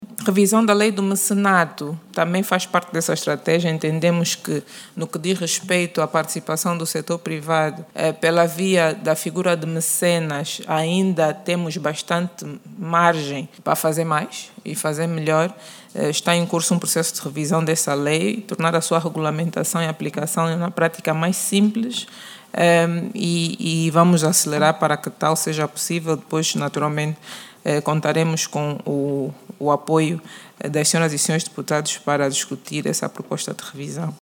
A informação foi transmitida na Assembleia Nacional pela Ministra das Finanças, que explicou aos deputados que o actual quadro legal ainda apresenta entraves burocráticos e limita a participação de potenciais mecenas.